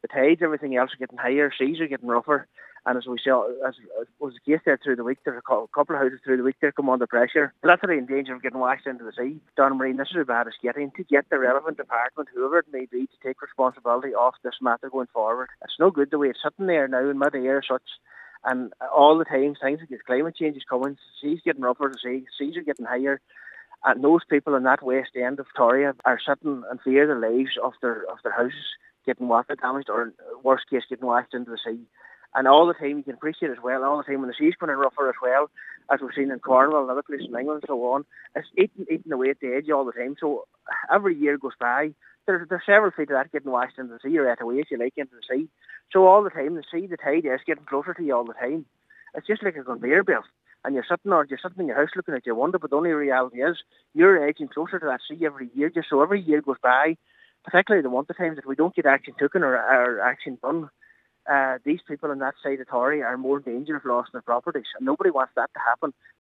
Cllr. McClafferty described the erosion of the coastline like a conveyor belt as the sea conditions worsen with climate change: